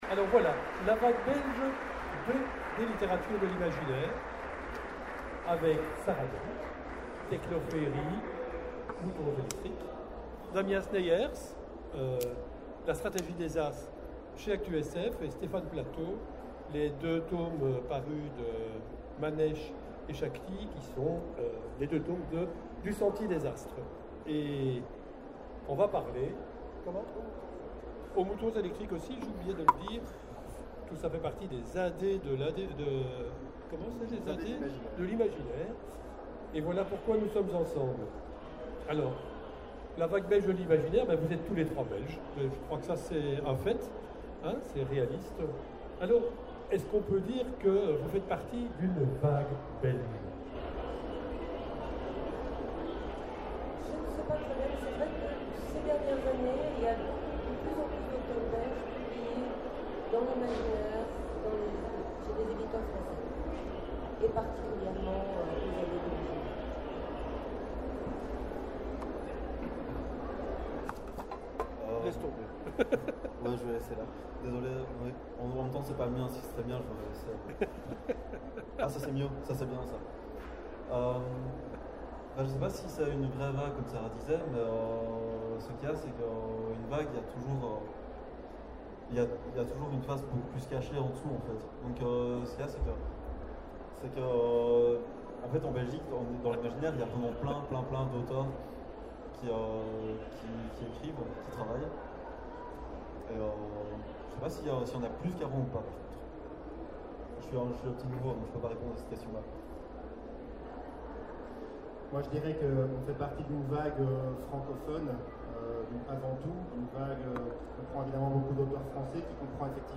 Foire du livre de Bruxelles 2017 : Conférence La vague Belges des Littératures de l’imaginaire